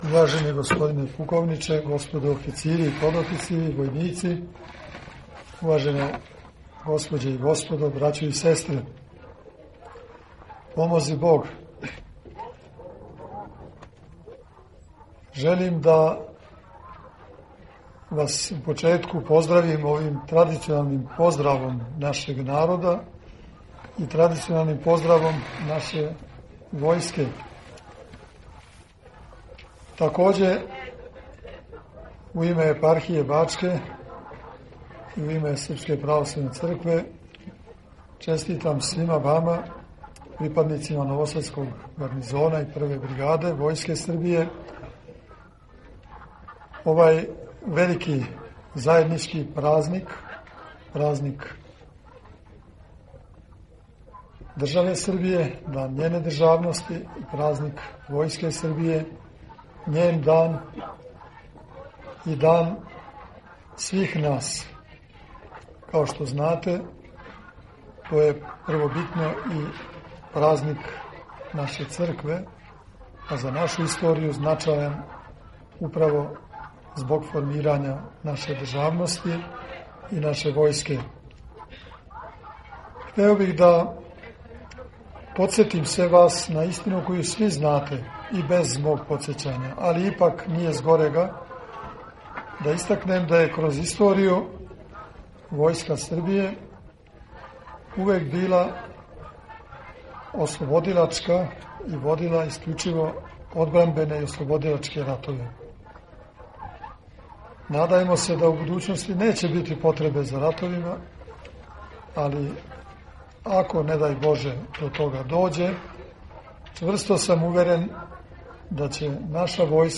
Поводом Дана Војске Србије, у Гарнизону Нови Сад приређена је свечаност којој су присуствовали представници угледних установа и институција.
Његово Преосвештенство Епископ бачки Господин др Иринеј честитао је припадницима новосадског гарнизона и Прве бригаде Војске Србије велики празник Сретења Господњег.